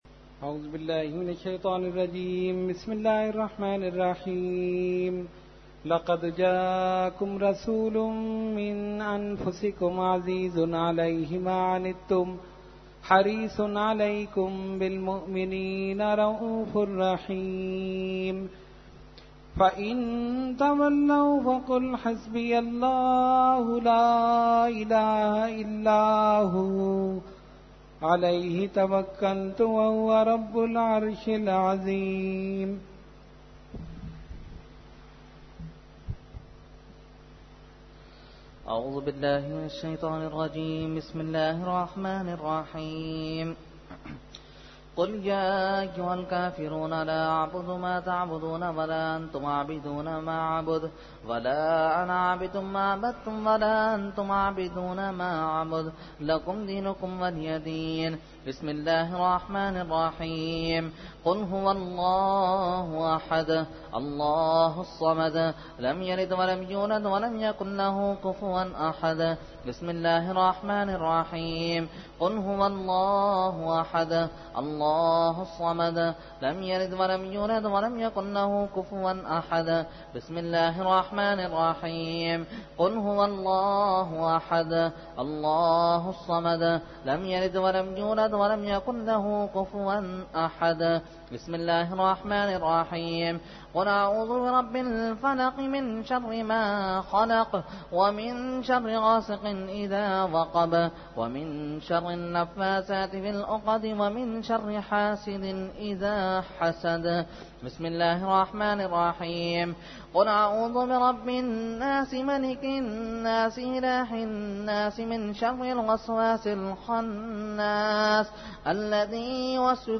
Fatiha and Dua – Memon Masjid Musleuddin Garden 2014 – Dargah Alia Ashrafia Karachi Pakistan